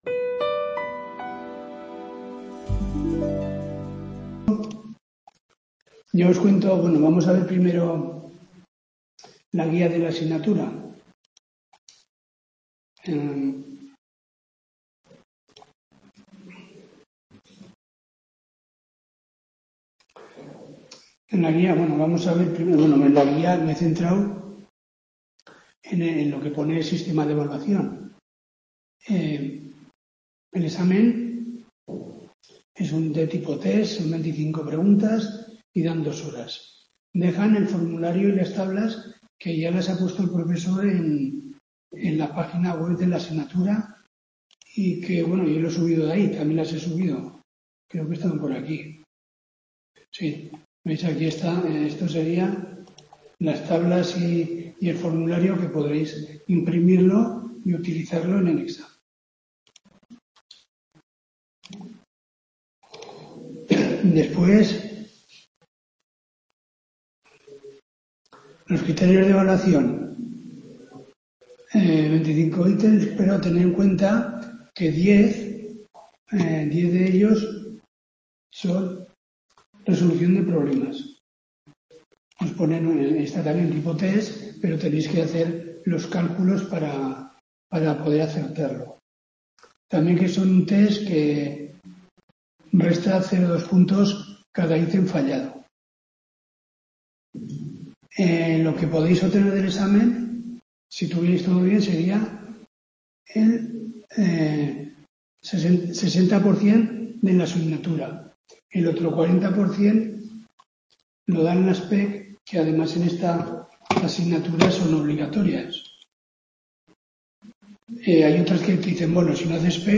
TUTORÍA DE 09/10/2024